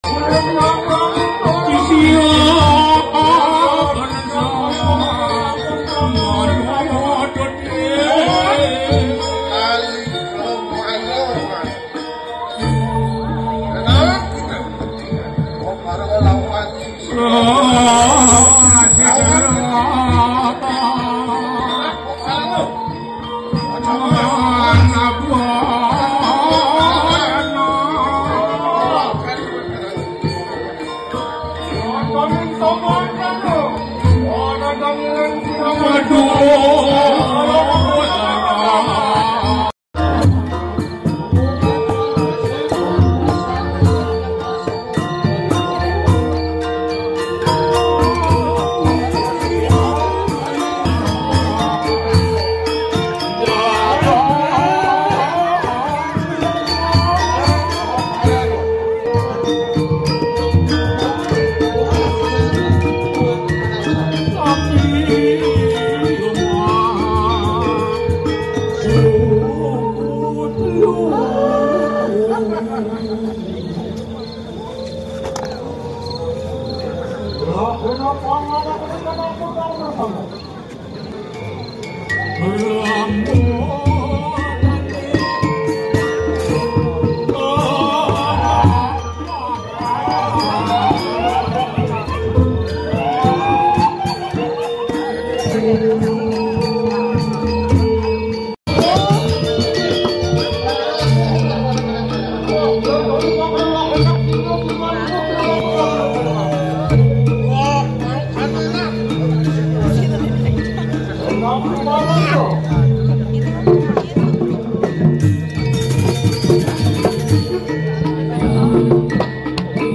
adegan dalam pentas calonarang yang sound effects free download
menceritakan para mahkluk halus pengikut Dewi Durga yang dipimpin oleh Kalika Maya sedang berpesta di kuburan memangsa mayat, pementasan calonarang dengan lakon "Nyomia" berlokasi di Pura Samuantiga, Bedulu